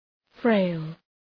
Shkrimi fonetik {freıl}